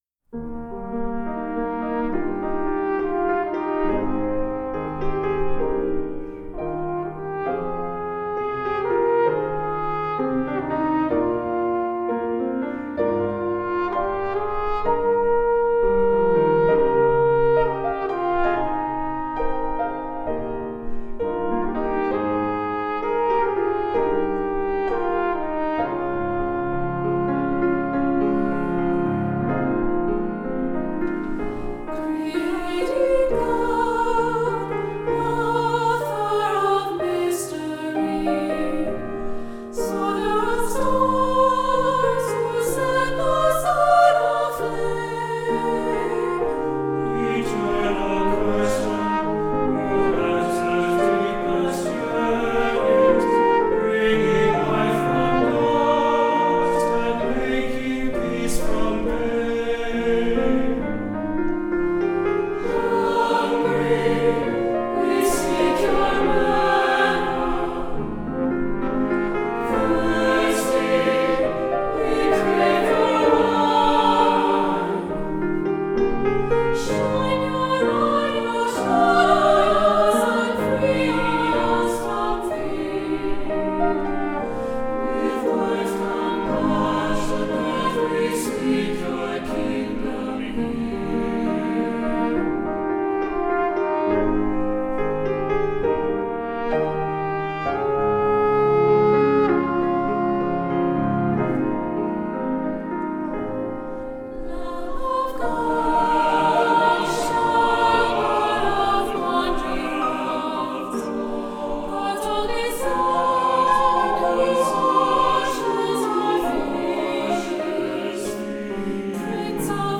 Voicing: SATB, French Horn and Piano